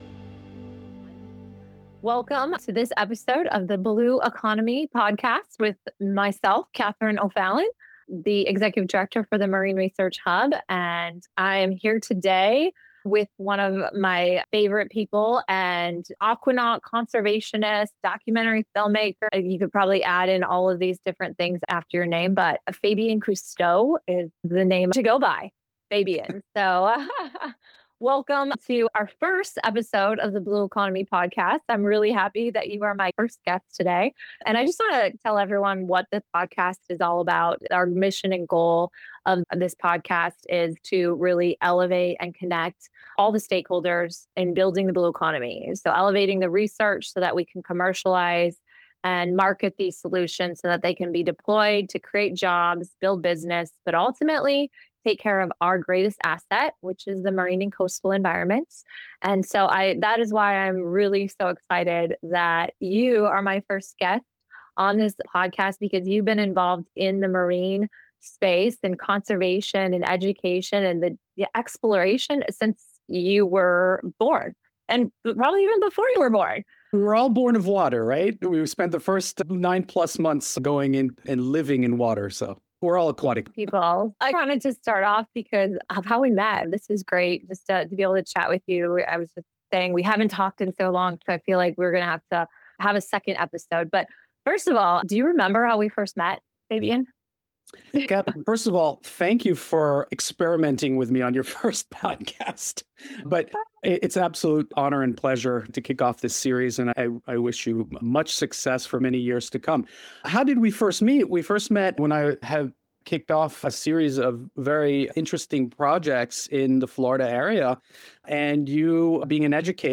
Fabien shares personal stories from his early diving days, reflects on the Cousteau family's enduring impact on marine science, and reveals how his groundbreaking projects like Mission 31 and the upcoming Proteus underwater habitat are pushing the boundaries of ocean innovation. Explore the vital role of the marine environment in driving Florida’s economy, why underwater research matters more than ever, and how educating future generations will be key to safeguarding our oceans.
this is a conversation you don't want to miss